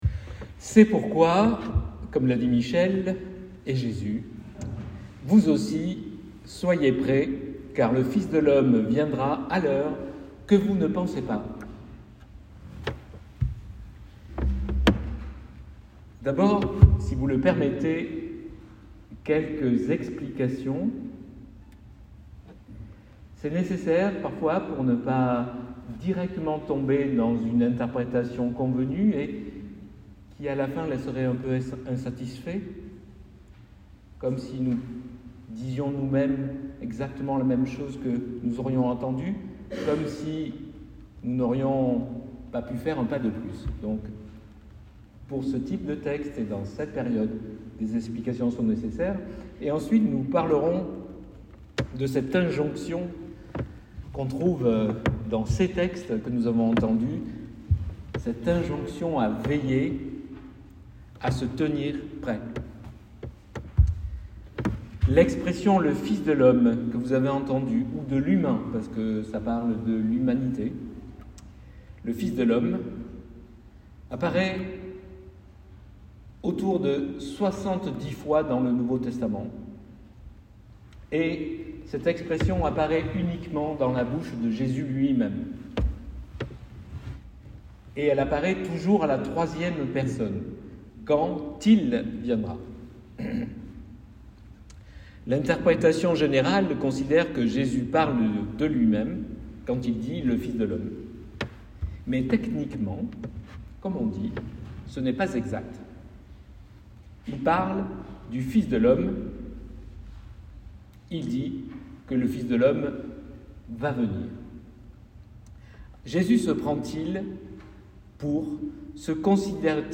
Prédication du 27 novembre 2022.mp3 (30.71 Mo) Prédication du 27 nov 2022.pdf (2.67 Mo)